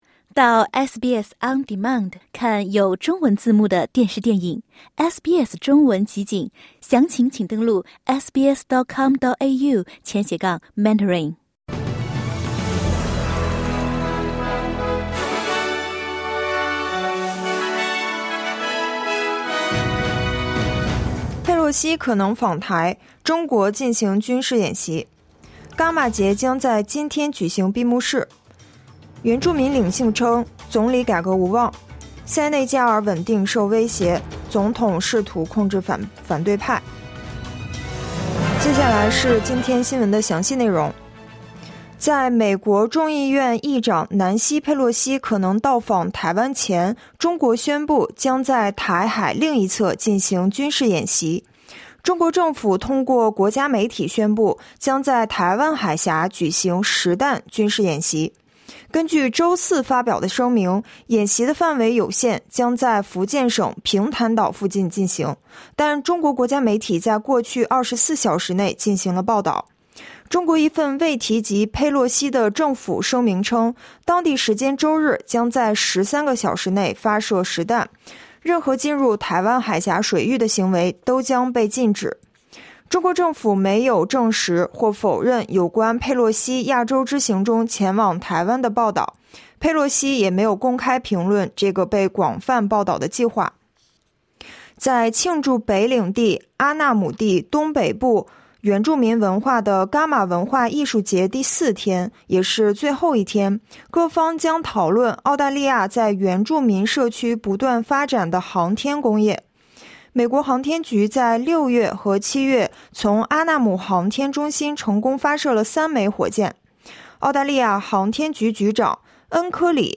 SBS早新聞（8月1日）